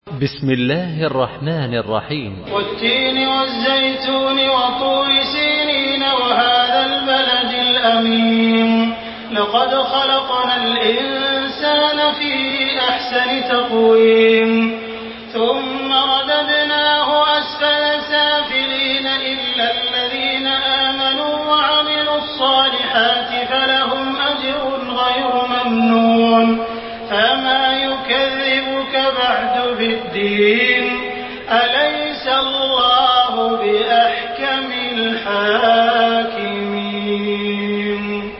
تحميل سورة التين بصوت تراويح الحرم المكي 1428
مرتل حفص عن عاصم